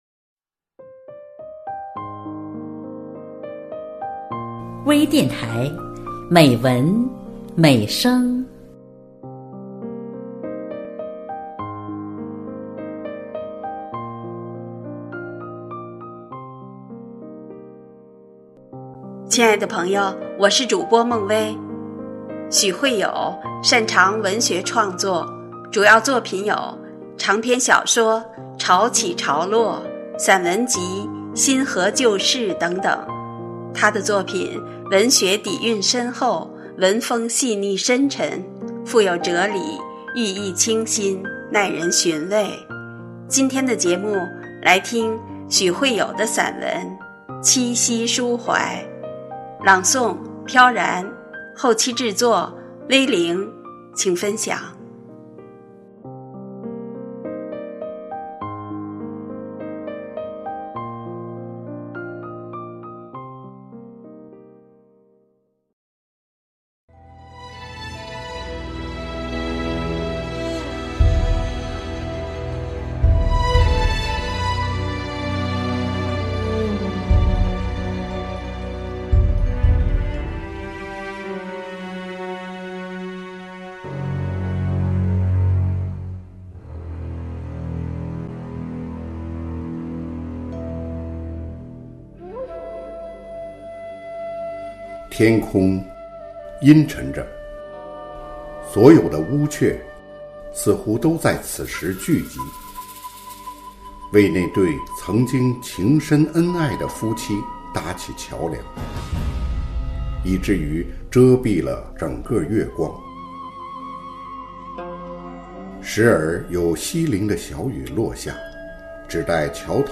多彩美文  专业诵读
美文美声    声音盛宴